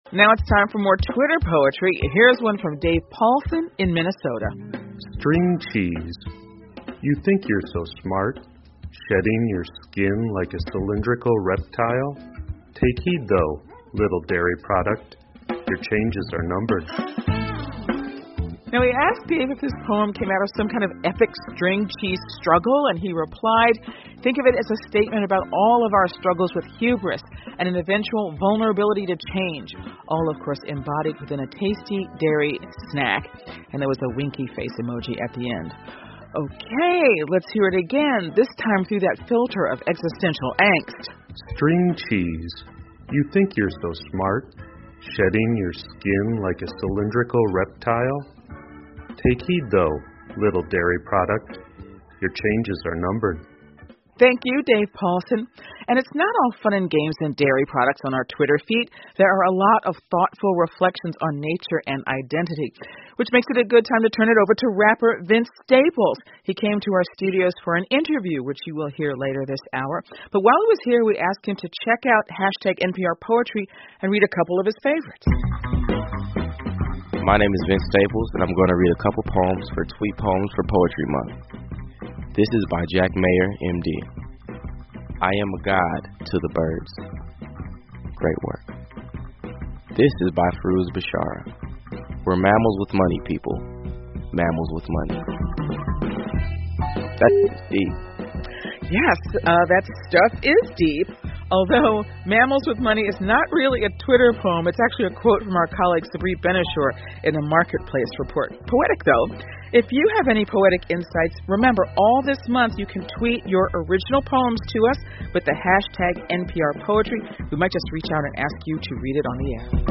NPR poetry Month: Vince Staples Reads His Favorite Twitter Poems 听力文件下载—在线英语听力室